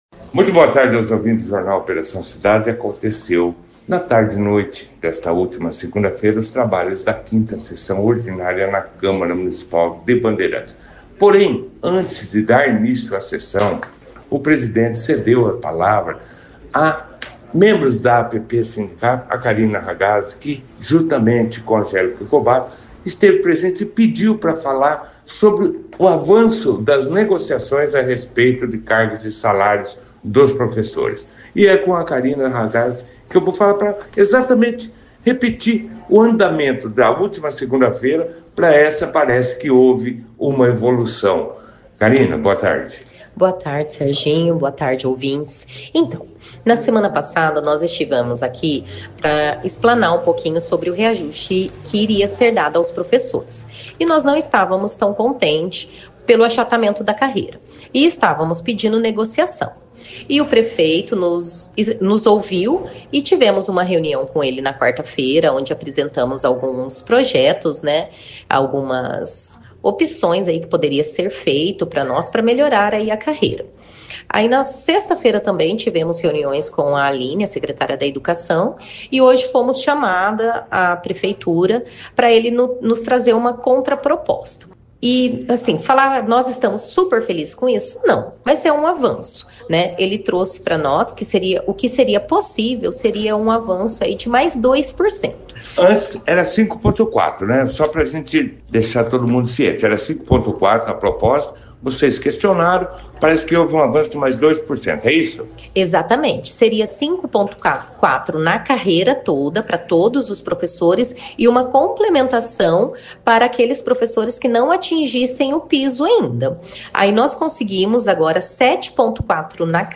5a-Sessao-Camara-de-Vereadores.mp3